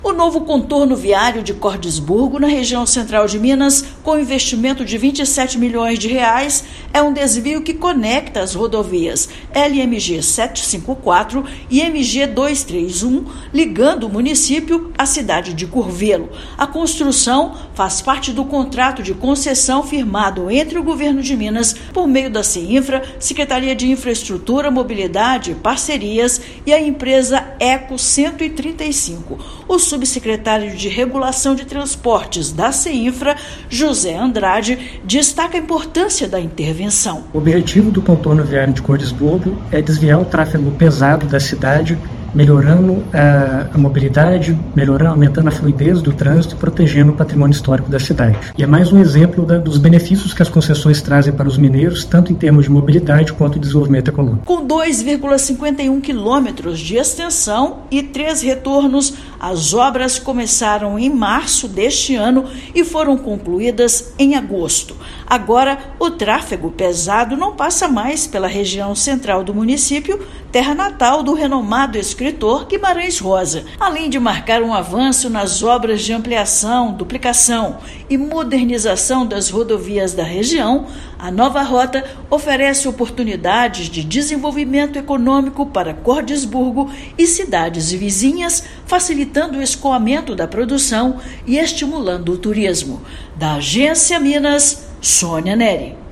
Com investimento de R$ 27 milhões, obra é resultado de concessão realizada pelo Governo de Minas e desvia tráfego pesado do centro da cidade natal de Guimarães Rosa. Ouça matéria de rádio.